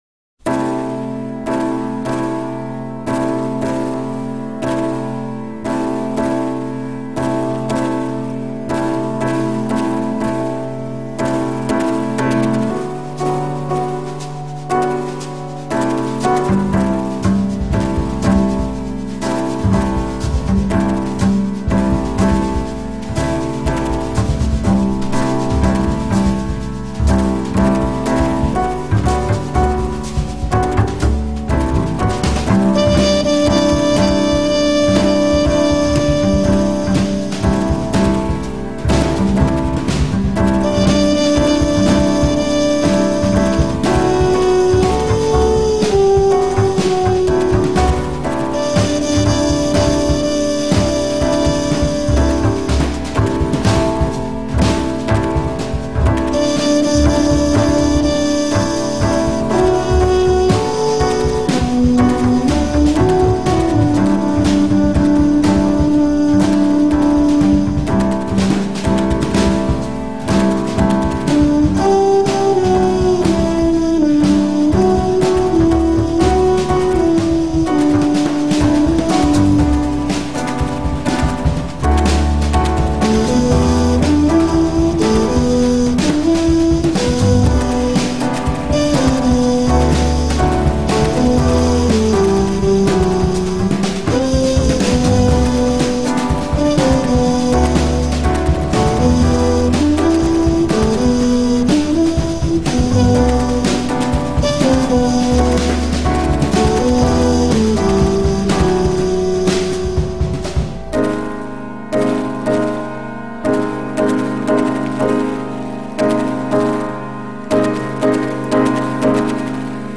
2. The soft trumpet tone.
3. The tempo change at 3:20.